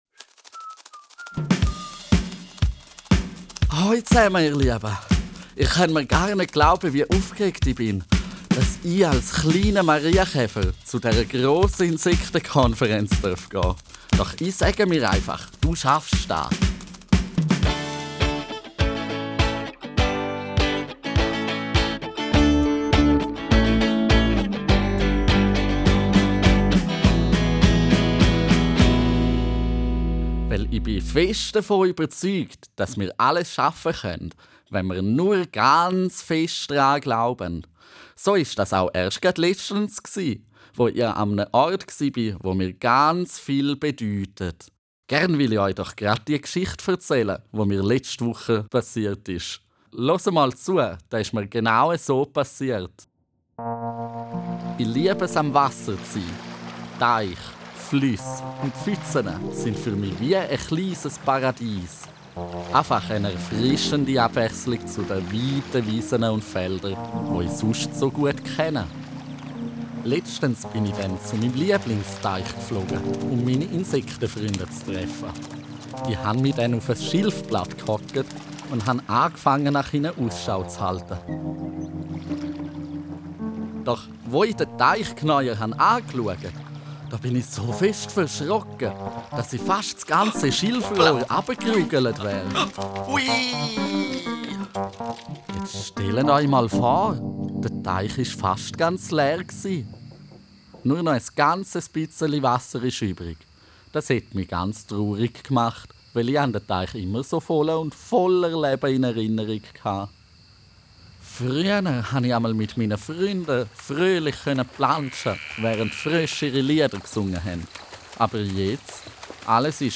Startet mit dem Hörspiel: Hört das Hörspiel über eure mitgebrachten Lautsprecher oder über Handys in kleinen Gruppen.